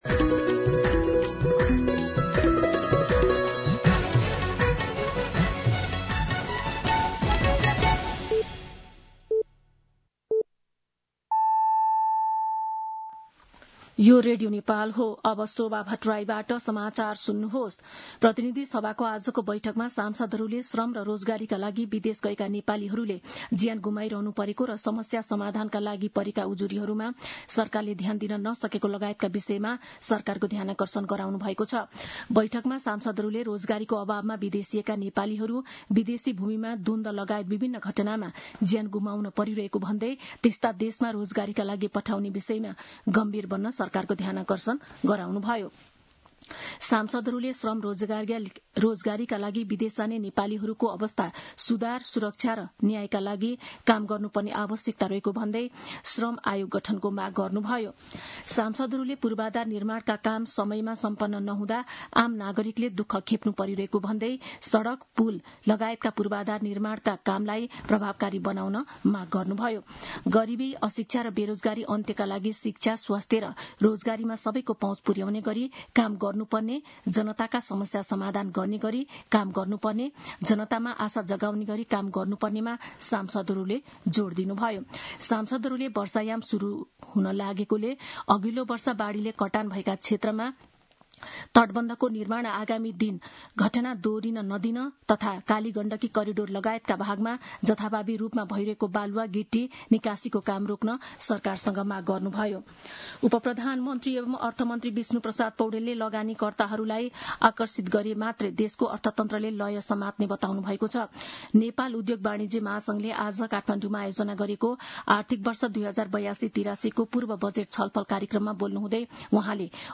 मध्यान्ह १२ बजेको नेपाली समाचार : २८ वैशाख , २०८२
12-pm-Nepali-News-3.mp3